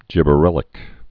(jĭbə-rĕlĭk)